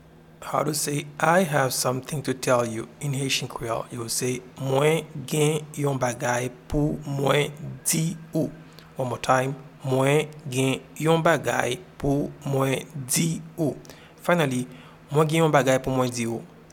Pronunciation and Transcript:
I-have-something-to-tell-you-in-Haitian-Creole-Mwen-gen-yon-bagay-pou-mwen-di-ou.mp3